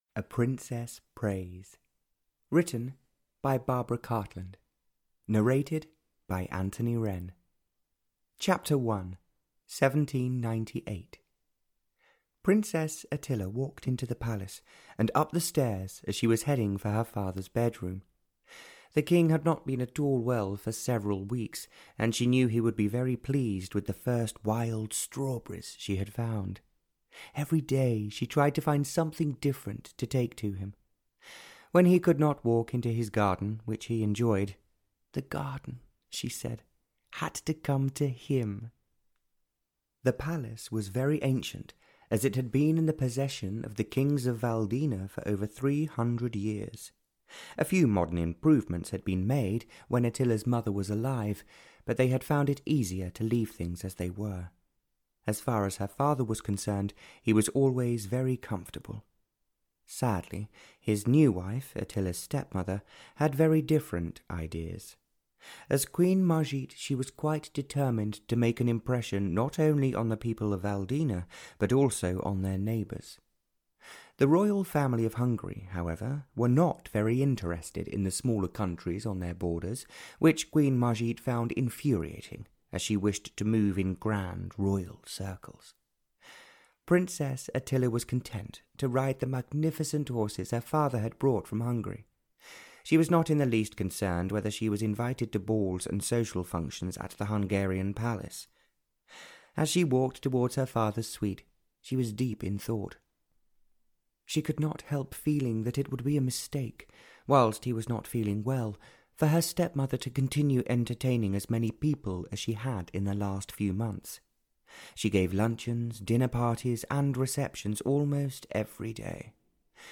A Princess Prays (EN) audiokniha
Ukázka z knihy